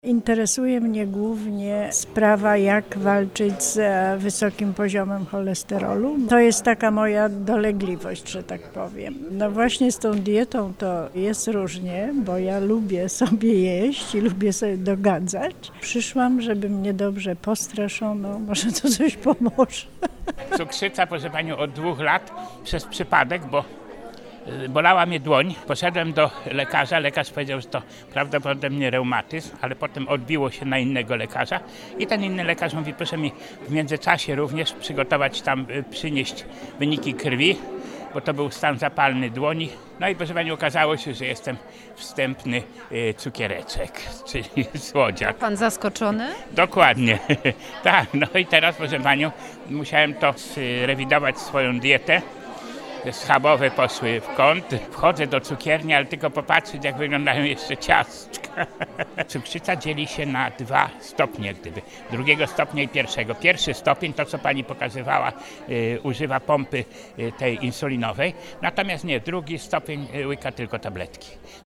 sonda_zdrowie.mp3